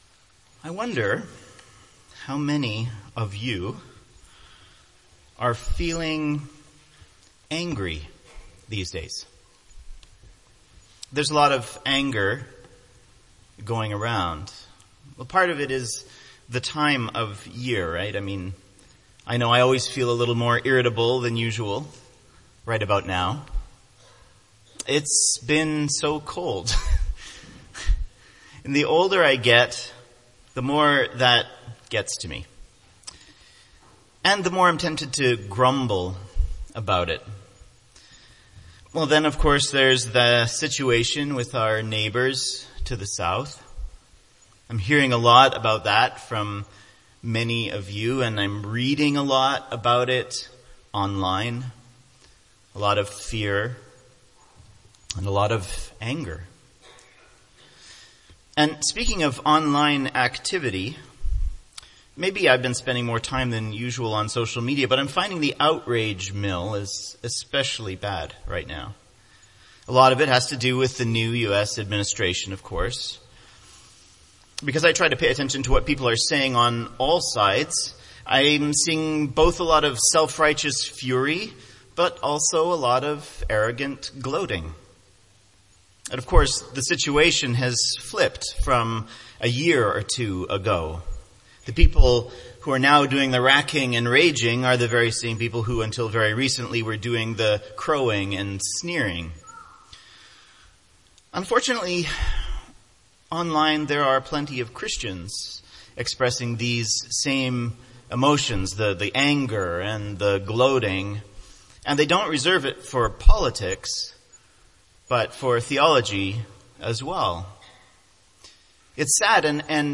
MP3 File Size: 12.9 MB Listen to Sermon: Download/Play Sermon MP3